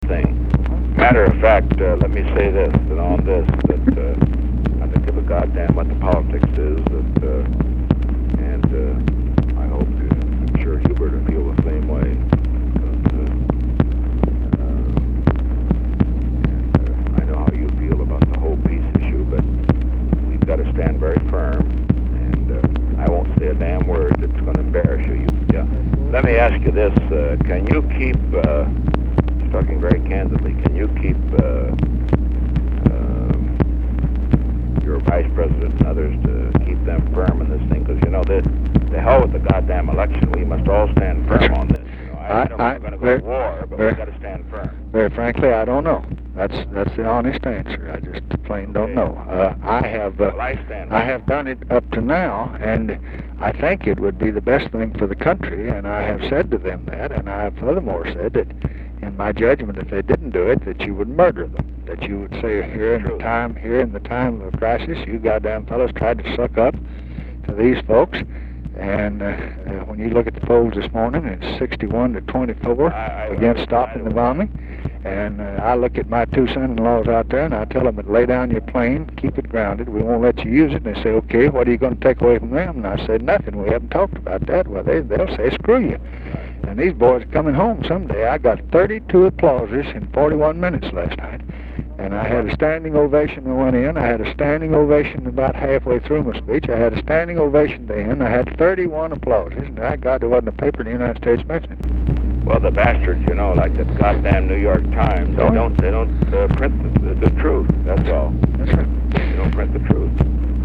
LBJ and GOP nominee Richard Nixon, discussing the politics of foreign policy, late Aug. 1968.